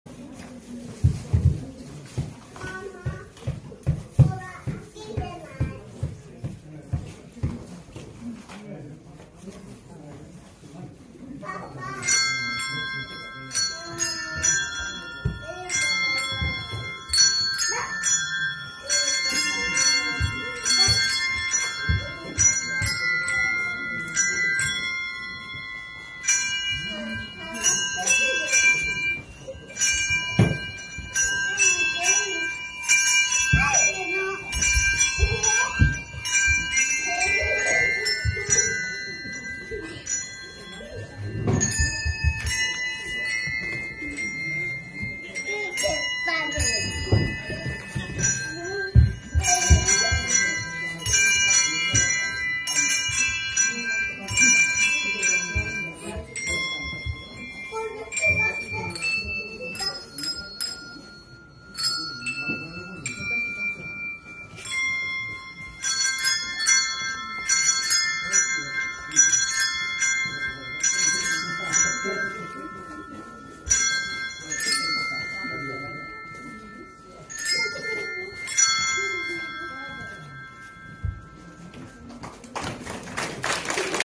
CSミュージックベルクラブ
教会学校(CS)リーダーがはじめた演奏グループです。